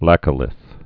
(lăkə-lĭth)